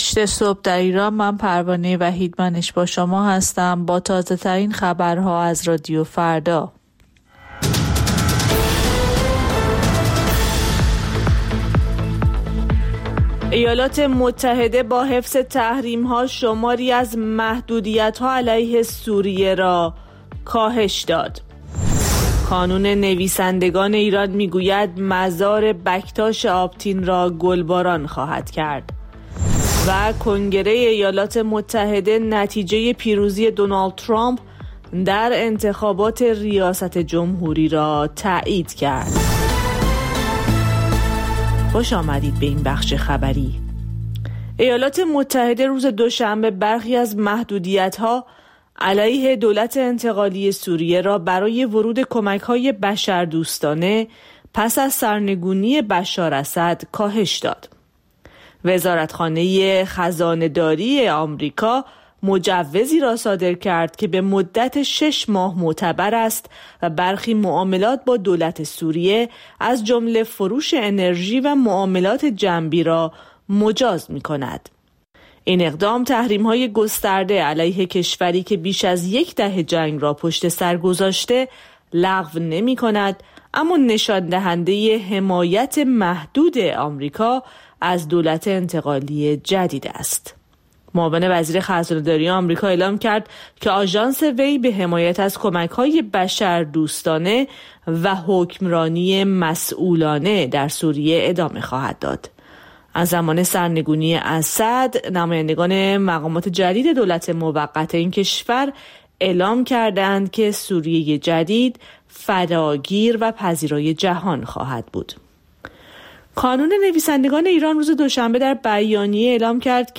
سرخط خبرها ۸:۰۰